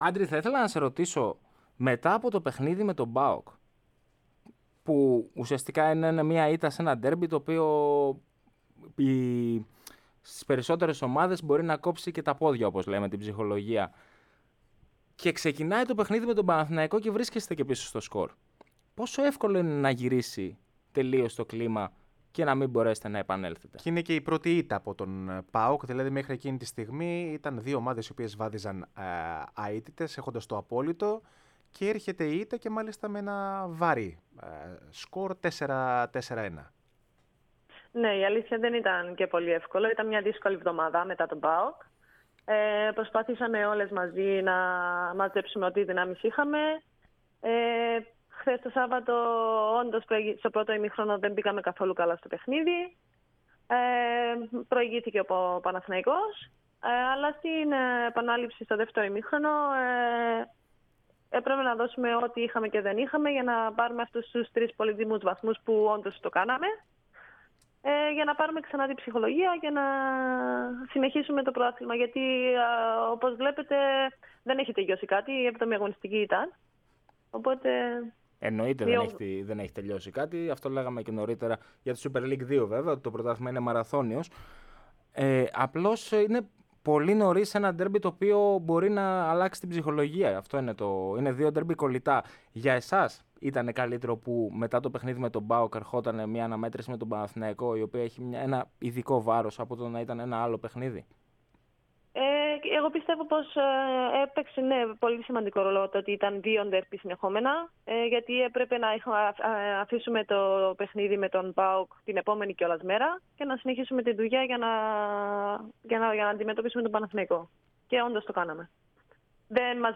φιλοξενήθηκε στον αέρα της ΕΡΑ ΣΠΟΡ στην εκπομπή "Μπάλα Παντού"